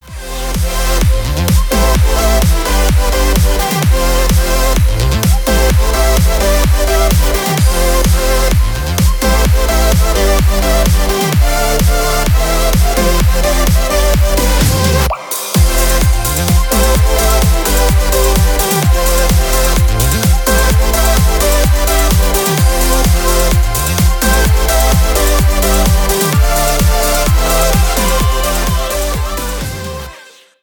без слов
весёлые